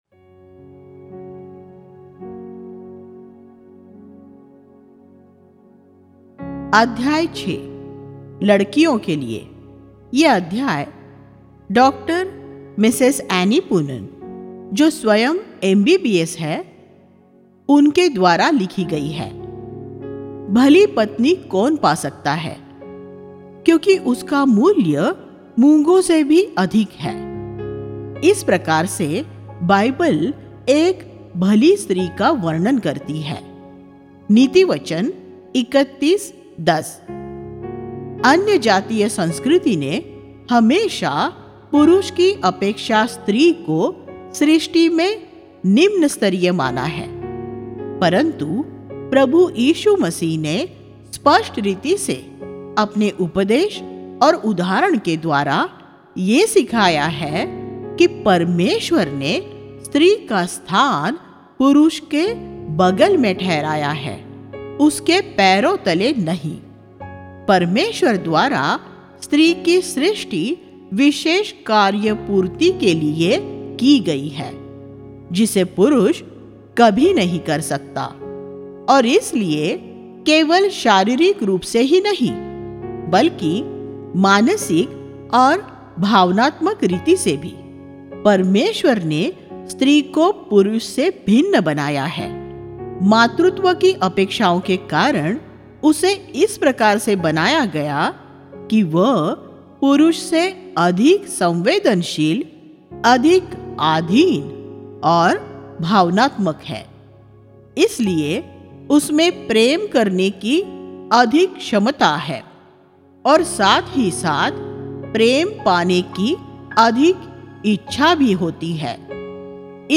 For Girls Only Sex, Love & Marriage Click here to View All Sermons इस शृंखला के उपदेश 1.